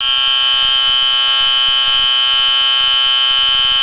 ZUMBADOR - SONIDO CONTINUO ELECTRÓNICO
Serie: AVISADORES ACÚSTICOS ELECTRÓNICOS
Zumbador electrónico, sonido electromagnético
Elevado rendimiento acústico
98dB
585 continuo